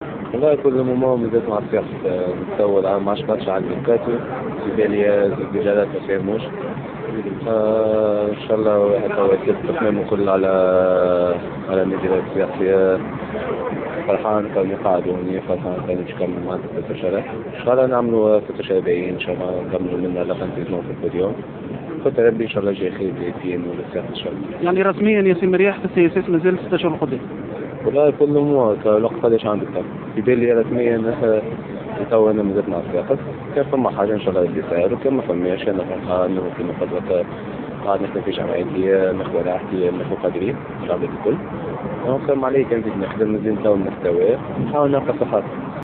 أكد مدافع النادي الصفاقسي ياسين مرياح في تصريح لجوهرة أف أم أنه سيواصل مع النادي الصفاقسي و لن يتحول إلى أي فريق خلال فترة الإنتقالات الشتوية الحالية.